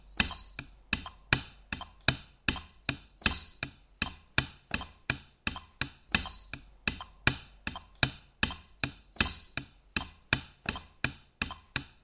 Ein schöner und häufiger Bass-Rhythmus.
Schritt 1: Alle acht Symbole im Handwechsel klopfen (rechts-links-rechts-links, Linkshänder bitte andersrum), die Viertelnoten viel lauter als die Pausen: